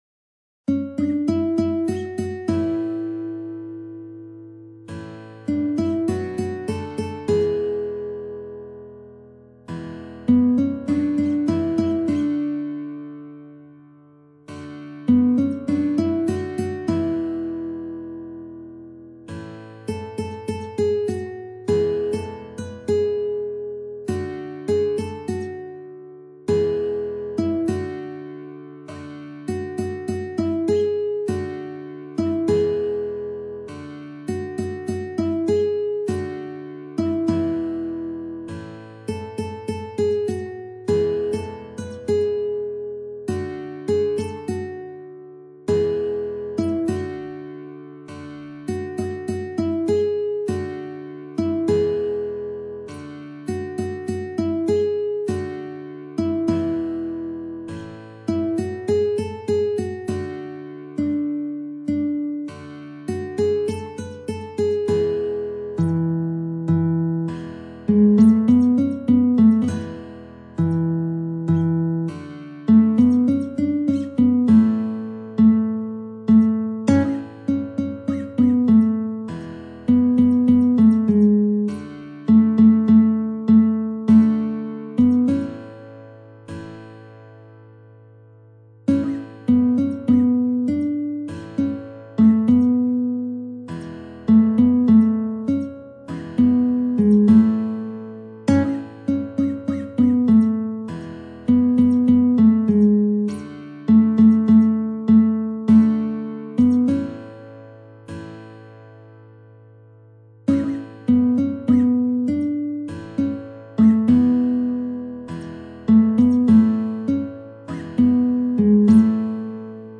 نت ملودی به همراه تبلچر و آکورد و شعر ترانه